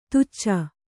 ♪ tucca